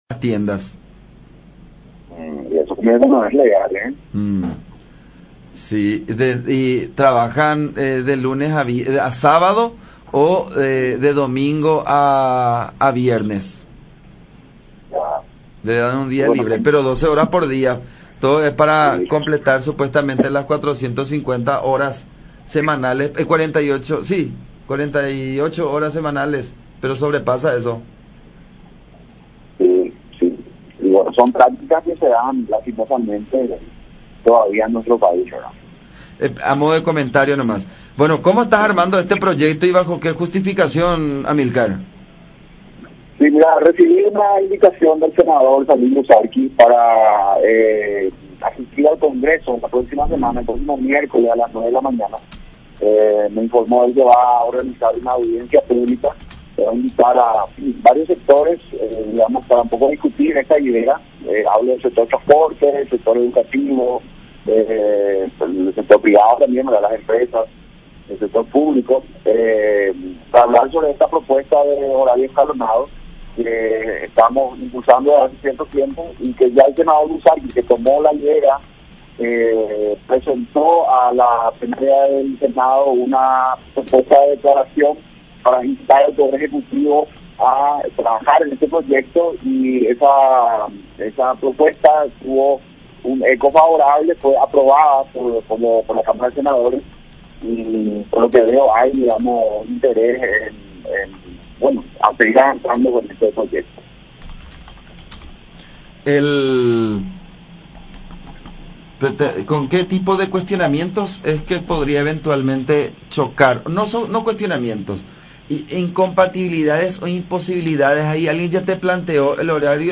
conversó con radio La Unión